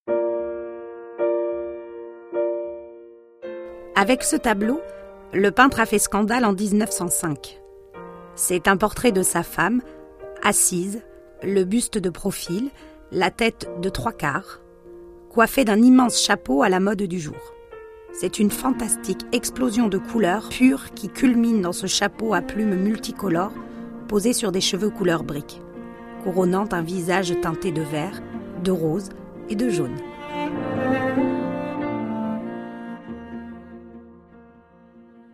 Voix off
Bande démo Voix